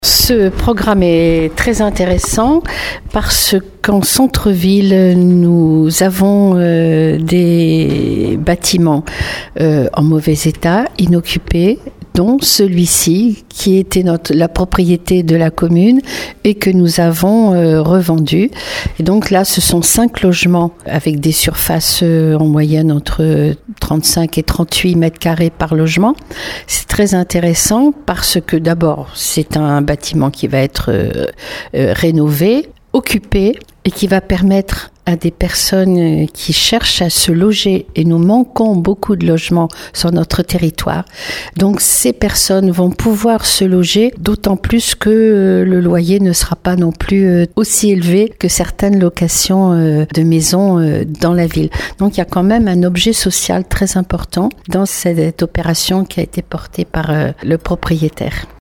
Un projet intéressant à plus d’un titre, comme le souligne la maire de Marennes-Hiers-Brouage Claude Balloteau :